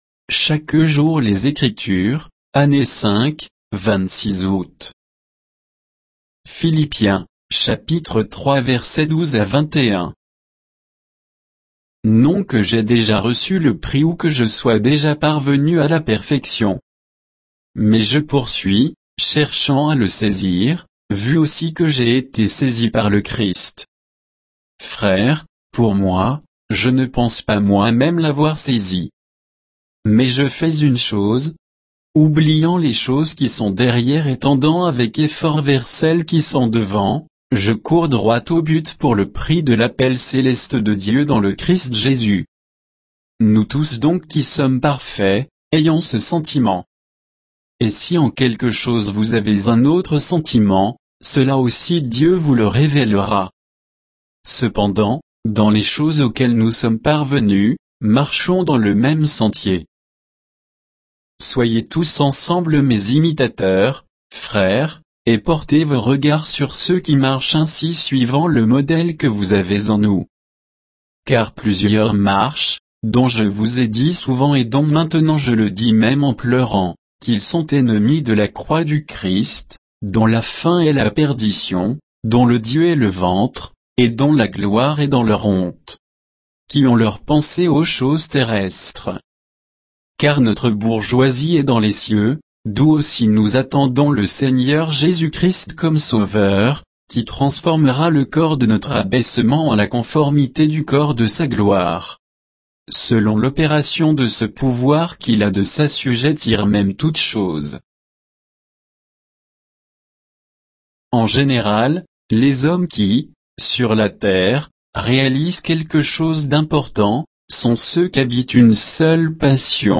Méditation quoditienne de Chaque jour les Écritures sur Philippiens 3, 12 à 21